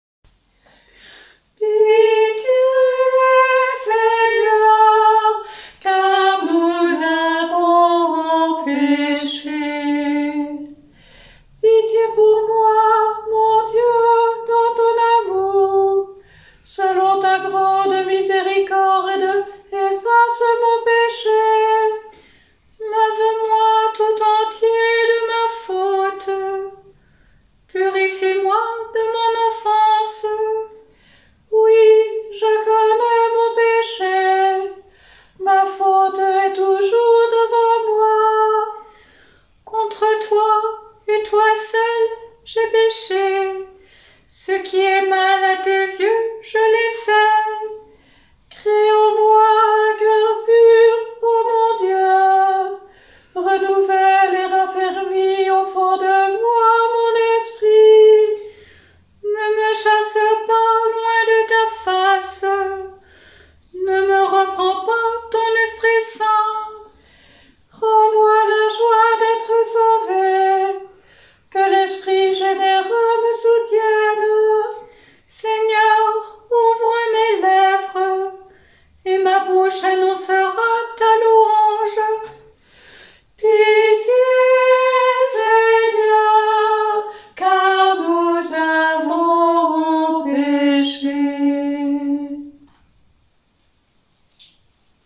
Vous trouverez ci-dessous les enregistrements de ces psaumes, avec les musiques de différents compositeurs, et des mises en œuvre sur 2 stiques (le verset est chanté sur une intonation de deux lignes) ou 4 stiques (le verset est chanté sur une intonation de quatre lignes)
Mercredi des cendres : Psaume 50